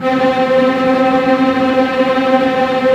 ZG3 TREM C4.wav